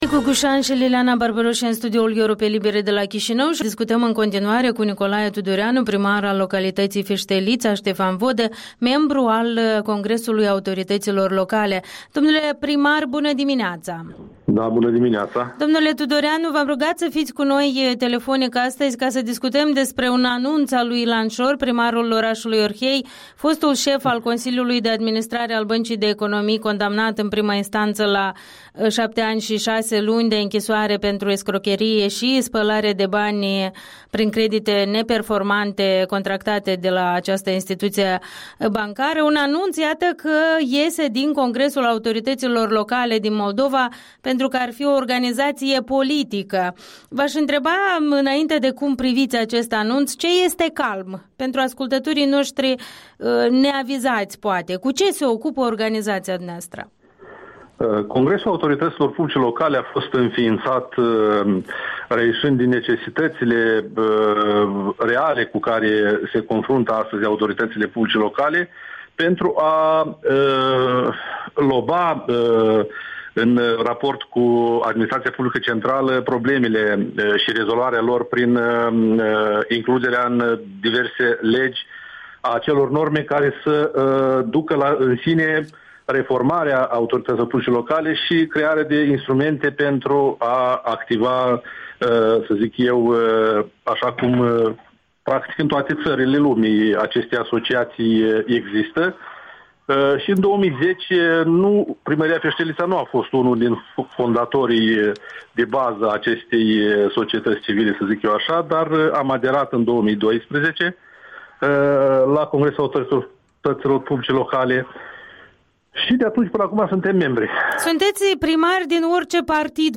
Interviul dimineții cu primarul Nicolae Tudoreanu (membru CALM)